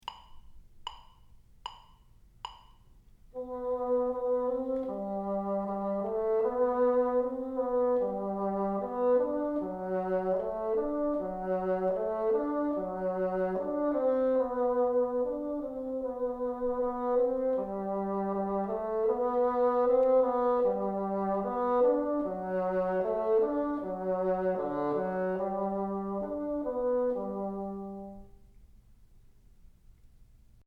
Unit 6 | Music and the Bassoon
Listen to LONDON BRIDGE on the sound clip. Learn to play it by ear in the key of G Major, starting on D2.